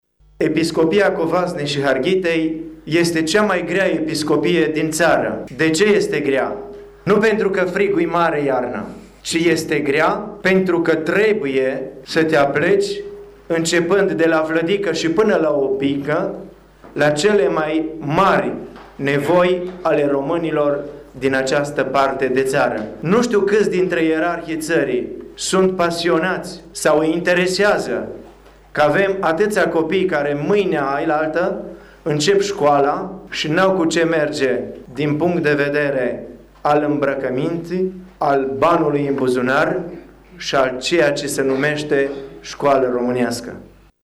Prea Sfinţitul Andrei Făgărăşanu, episcopul Covasnei şi Harghitei, a declarat azi, în cursul lucrărilor Universităţii de Vară de la Izvoru Mureşului, că este foarte dificil să fie alături de enoriaşi şi să le rezolve problemele.